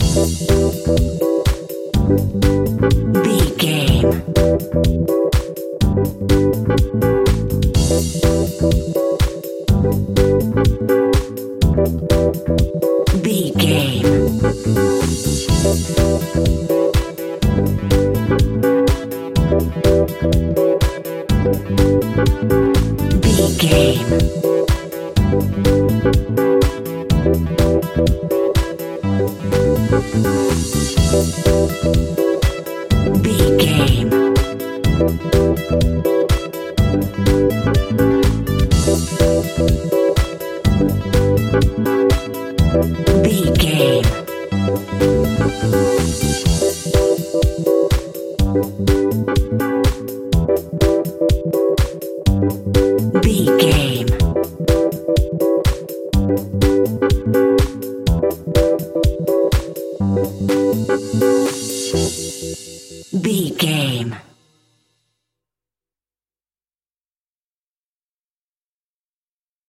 Aeolian/Minor
groovy
uplifting
energetic
funky
bass guitar
drums
electric piano
synthesiser
funky house
disco
upbeat
instrumentals